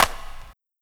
Car Wash Clap3.wav